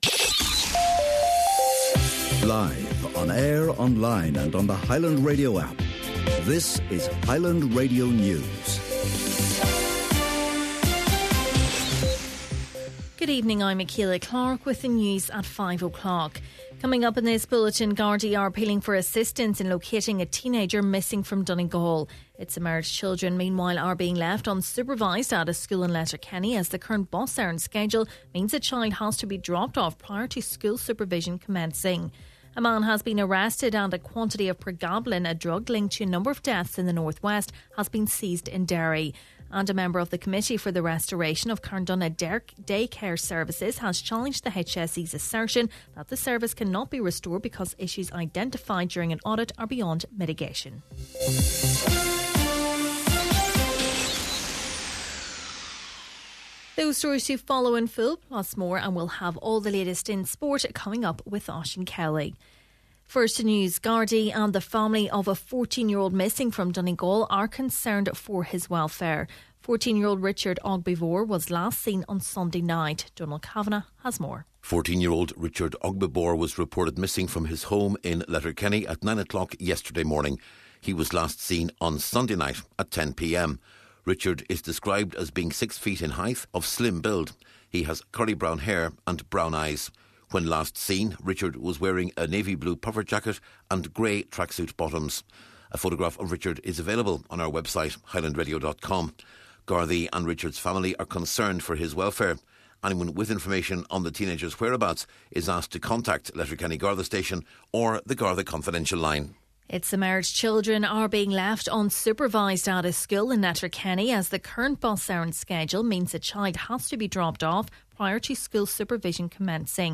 Main Evening News, Sport and Obituaries – Wednesday October 4th